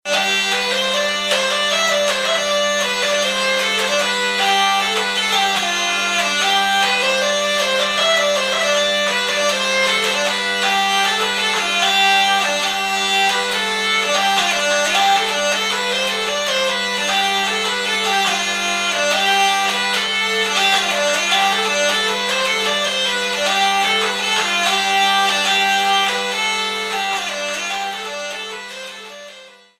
vielle Pajot
Vielle Pajot, datée 1886: